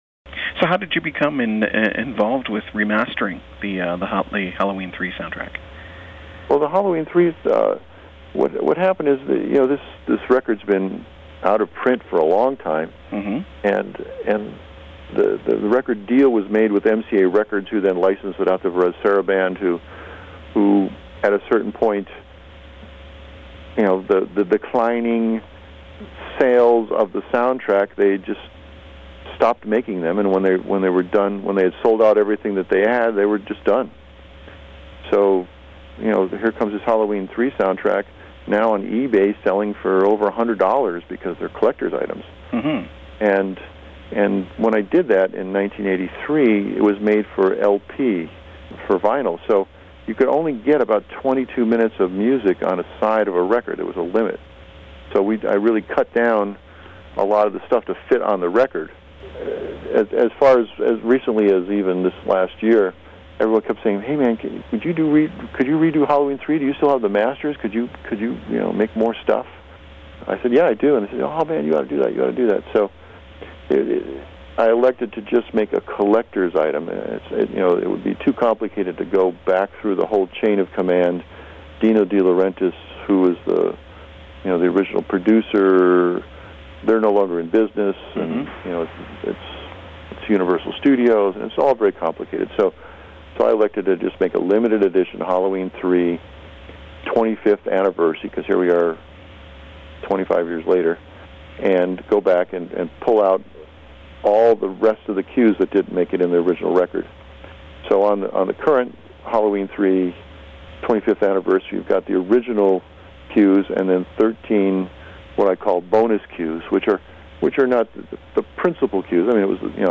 Interview with Alan Howarth about Halloween 3 and other interesting topics.